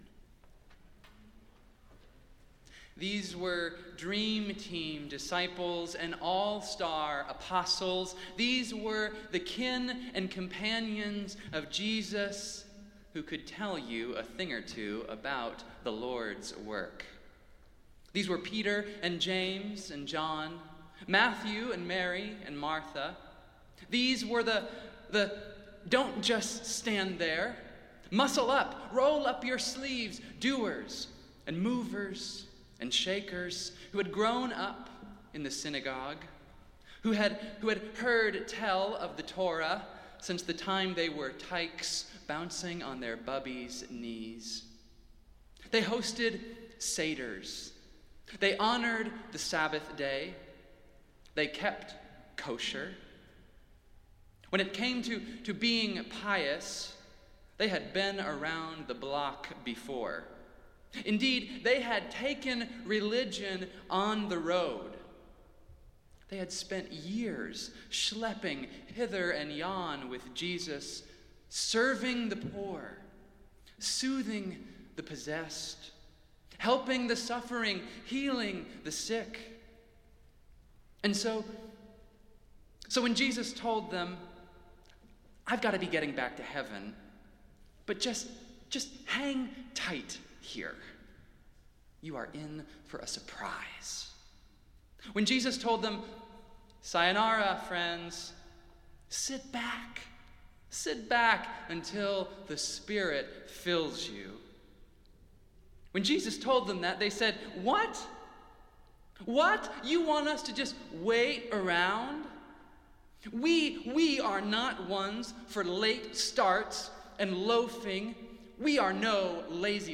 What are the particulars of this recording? Festival Worship - Pentecost Sunday